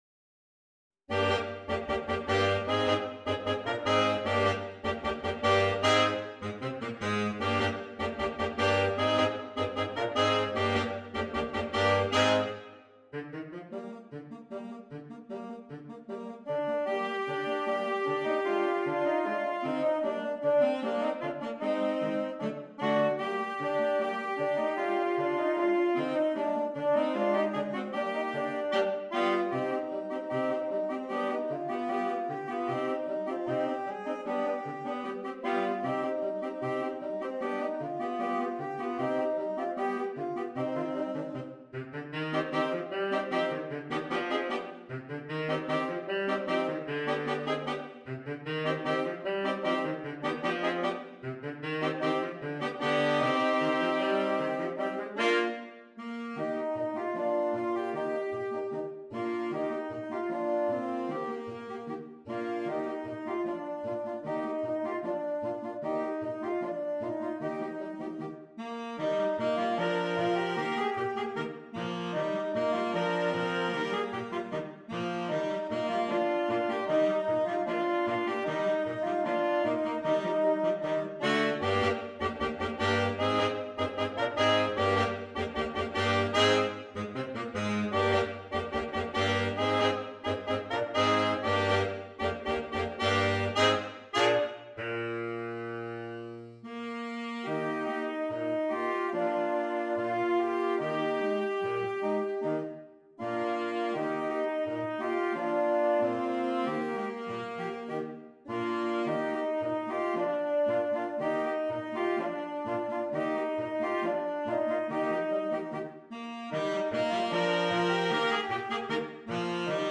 Voicing: Saxophone Quartet (AATB)